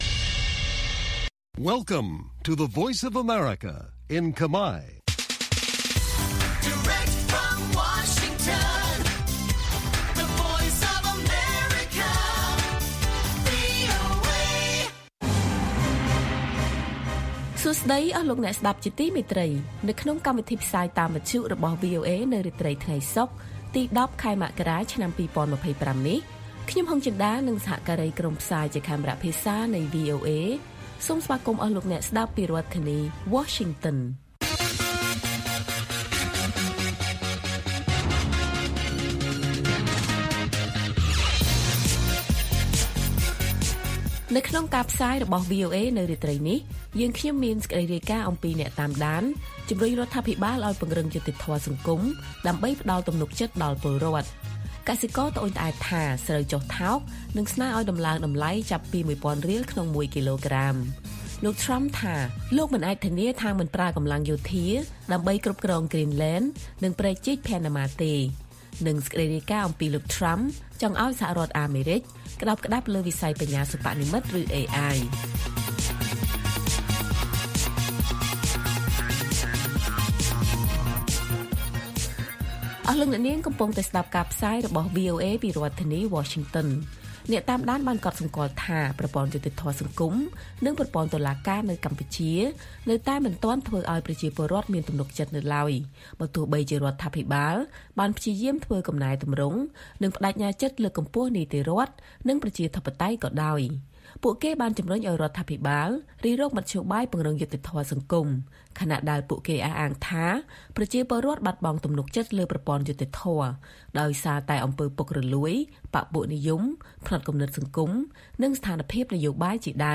ព័ត៌មានពេលរាត្រី ១០ មករា៖ អ្នកតាមដានជំរុញរដ្ឋាភិបាលឱ្យពង្រឹងយុត្តិធម៌សង្គមដើម្បីផ្តល់ទំនុកចិត្តដល់ពលរដ្ឋ